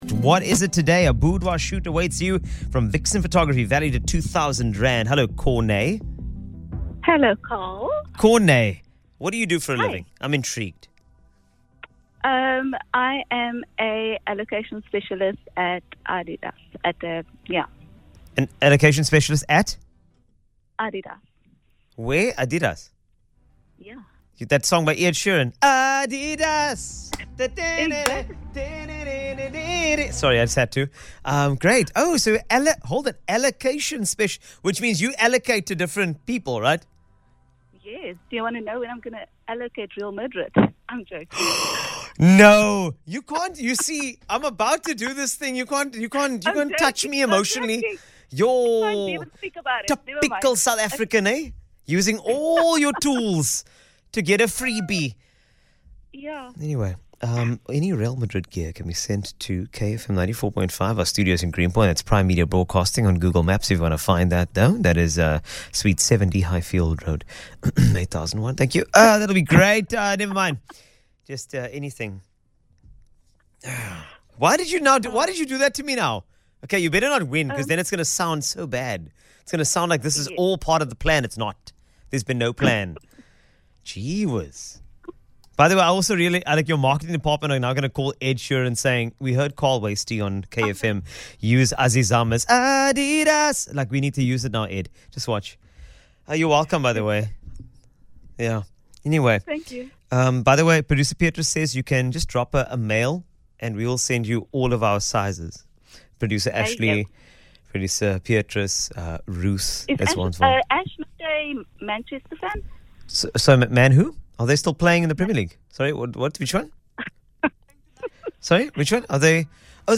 Vixen Photography radio winner giveaway segment